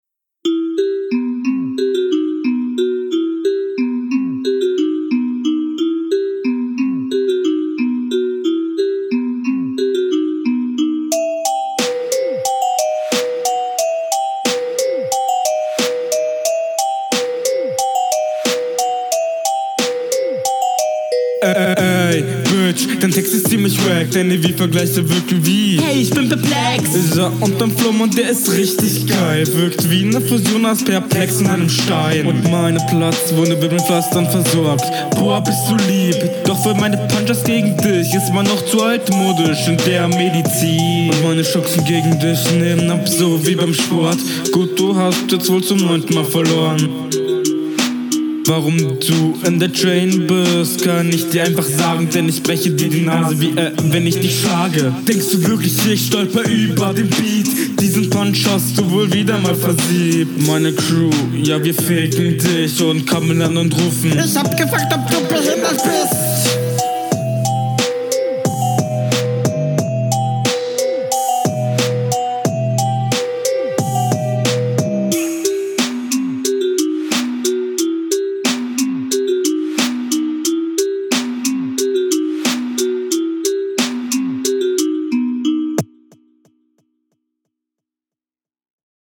also fand das echo bisschen zu stark eingestellt, bei der mische. aber man konnte dich …